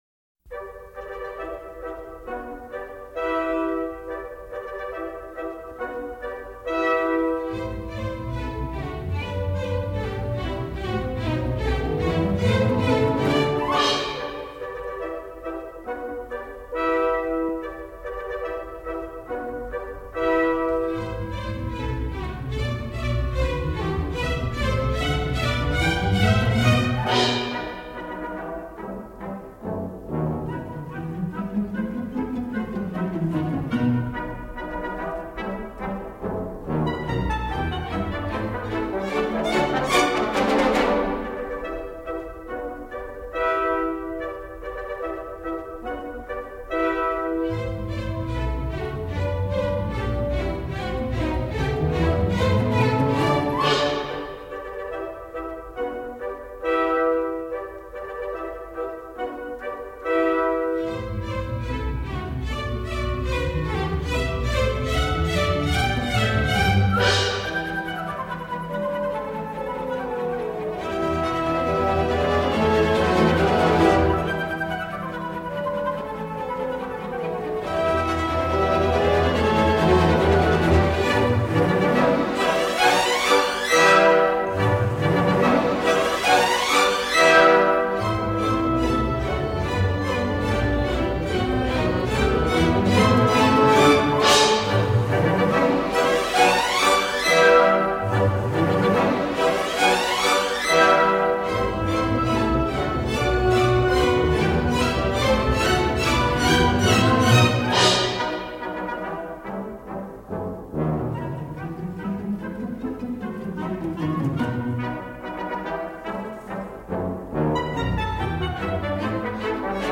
音色更接近模拟(Analogue)声效
强劲动态音效中横溢出细致韵味